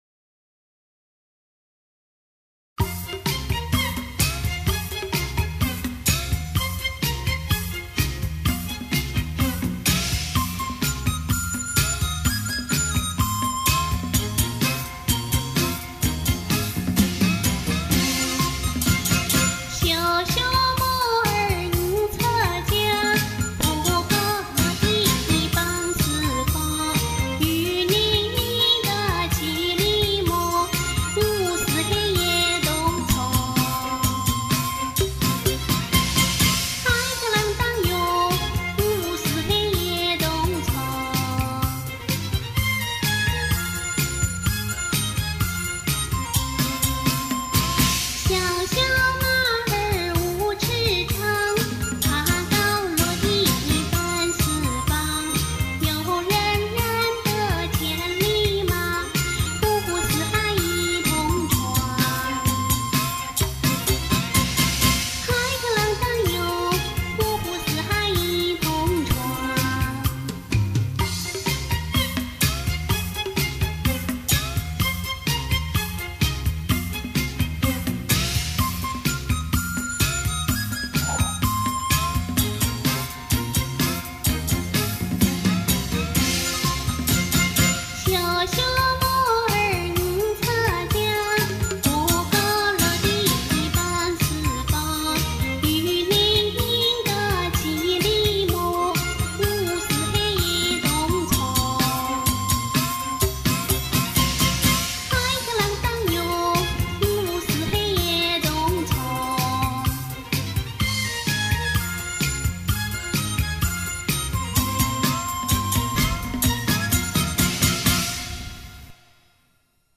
一首乡土气息浓郁的《马灯调》